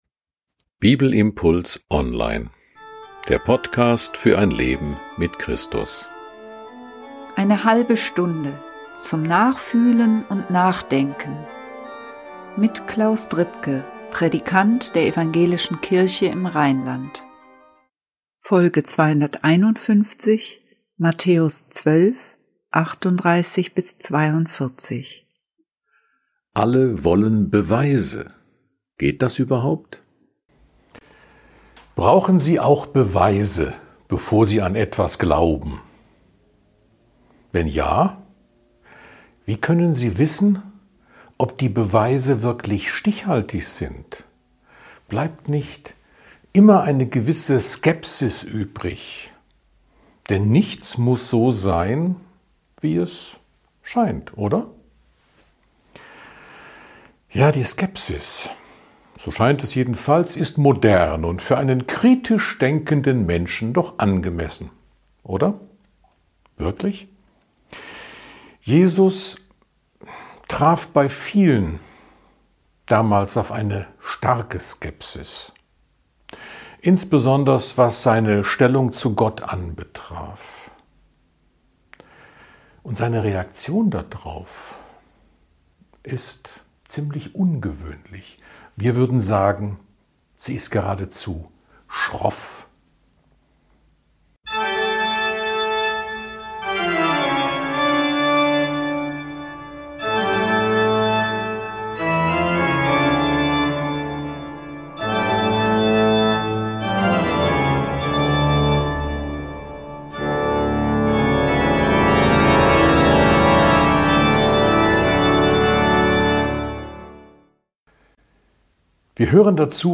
Dann ist es doch eigentlich verständlich, dass die Schriftgelehrten irgendwelche Beweise oder "Zeichen" von ihm fordern - oder? Jesus sieht das ganz anders. Ein Bibelimpuls zu Matthäus 12, 38-42.